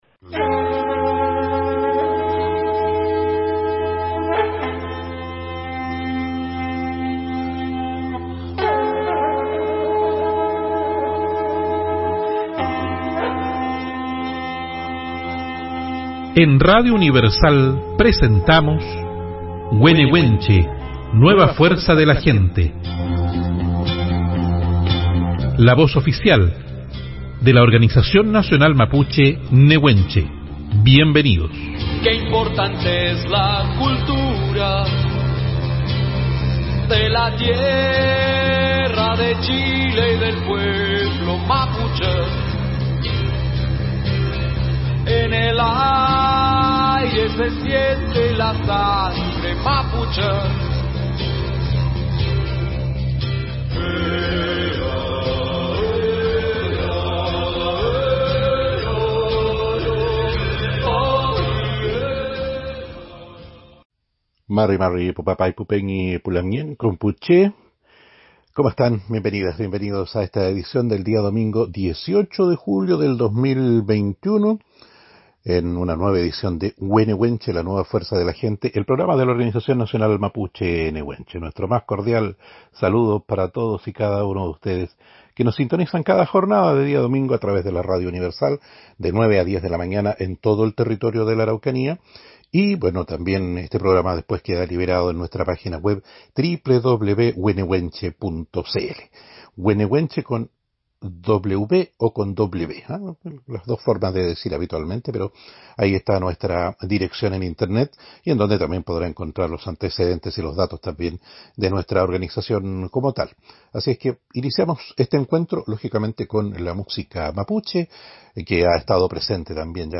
Te invitamos a volver a escuchar el programa de radio We Newenche de este domingo 18 de Julio 2021.